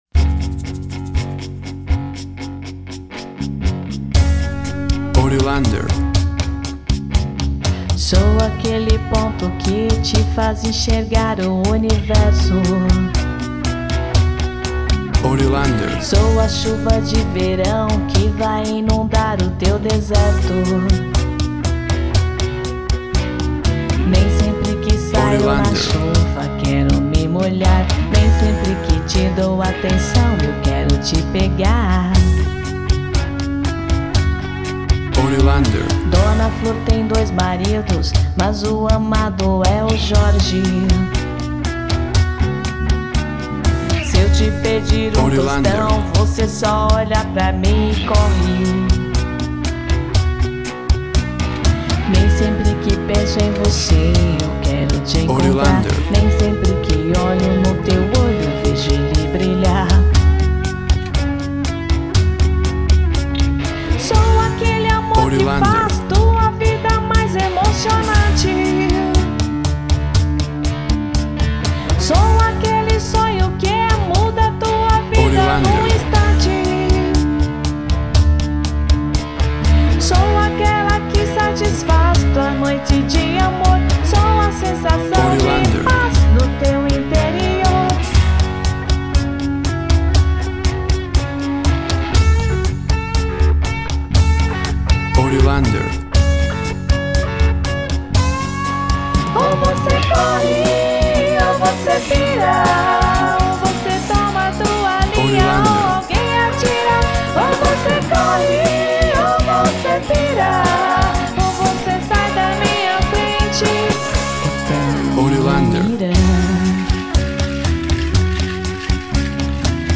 Pop Rock song.
Tempo (BPM) 120